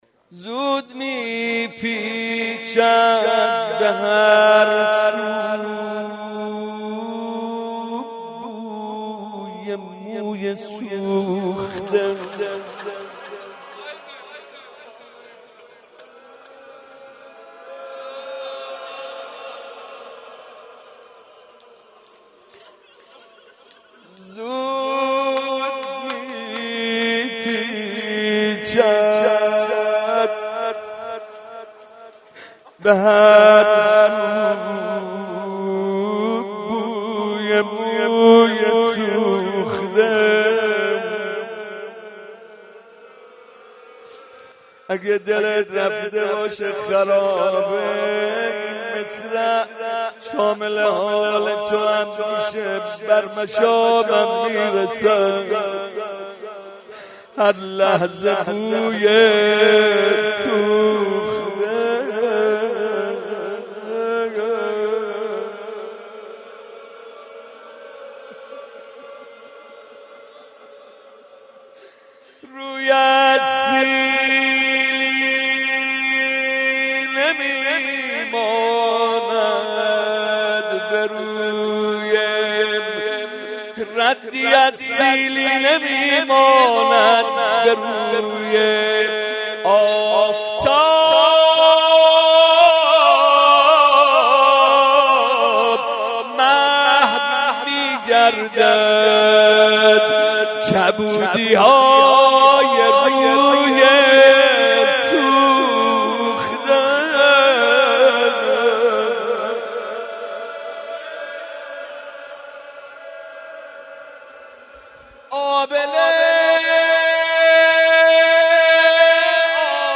مناسبت : شب سوم رمضان
قالب : روضه
02.rozeh.mp3